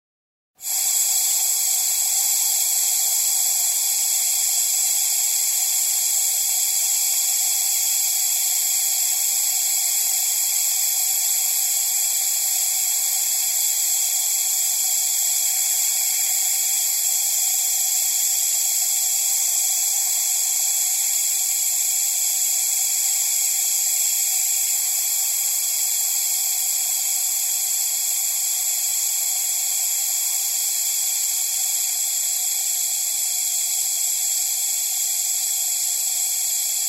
Звуки увлажнителя воздуха
Звук выходящего воздуха